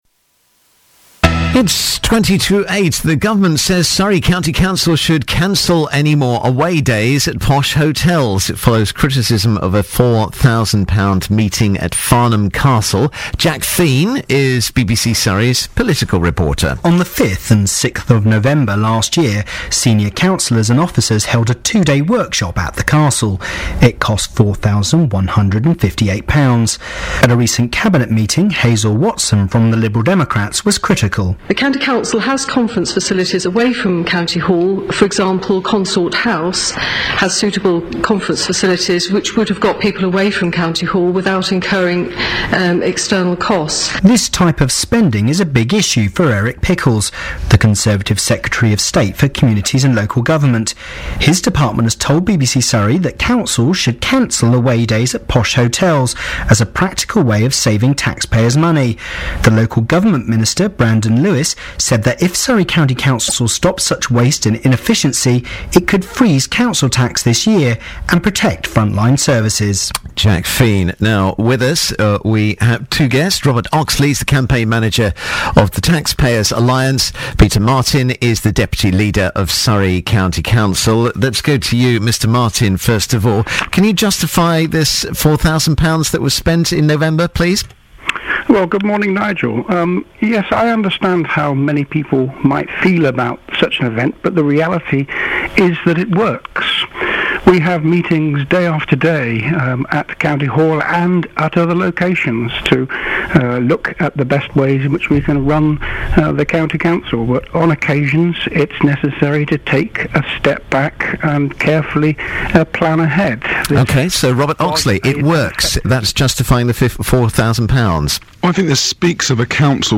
BBC Surrey interview about council’s decision to hold event at Farnham Castle